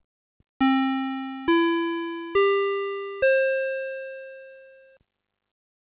洪水・土砂災害等 高齢者等避難 チャイム音を2回繰り返す
nobori_chaimu.mp3